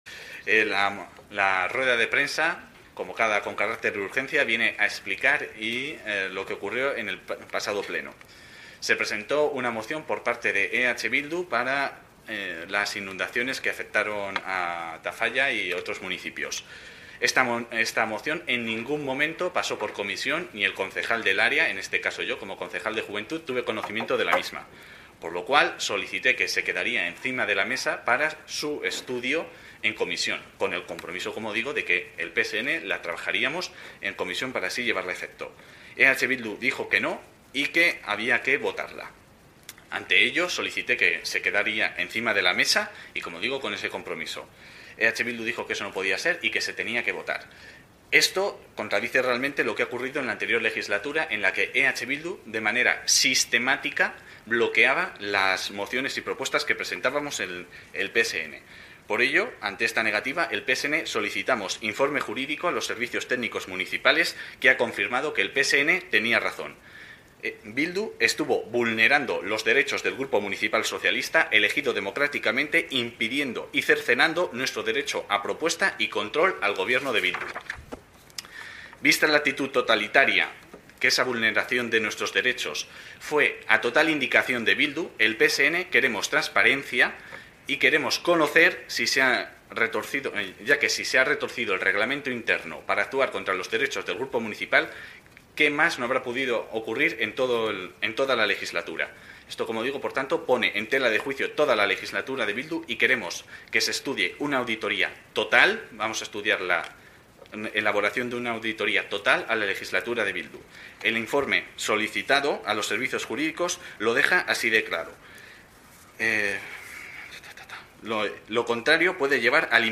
A continuación reproducimos en su integridad la breve rueda de prensa del Psn.